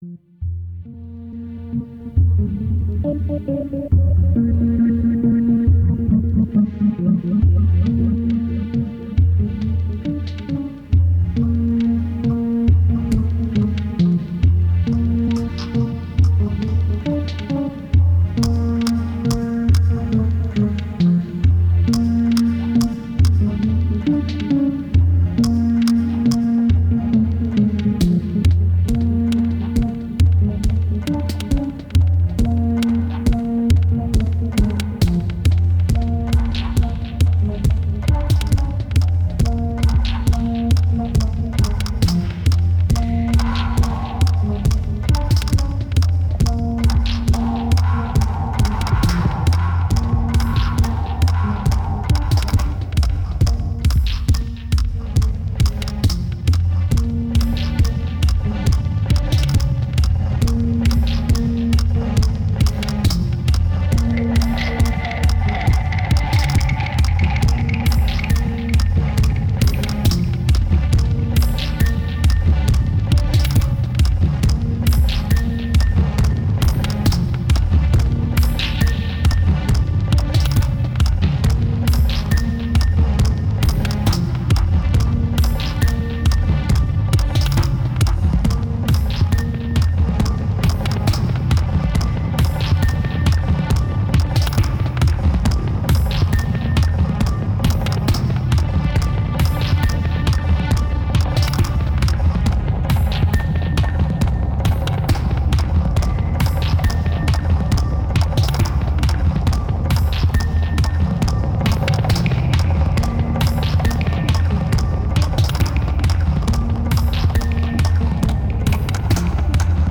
2259📈 - -34%🤔 - 137BPM🔊 - 2009-04-24📅 - -321🌟